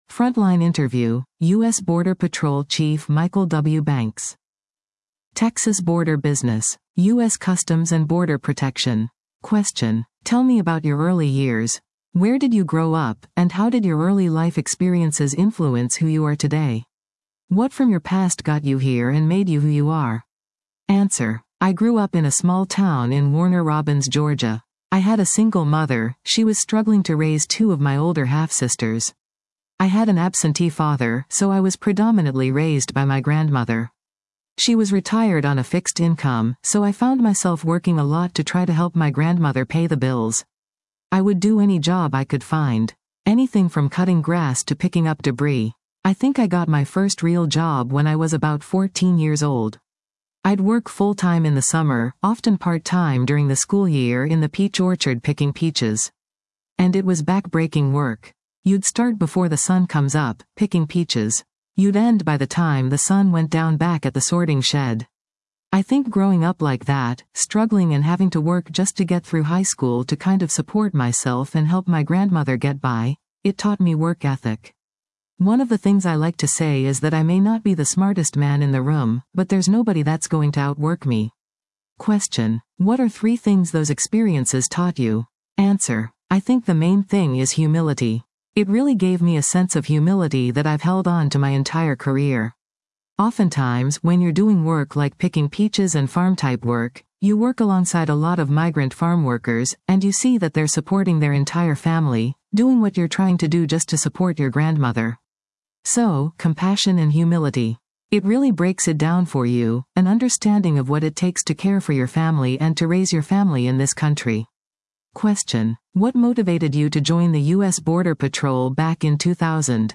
Frontline Interview: U.S. Border Patrol Chief Michael W. Banks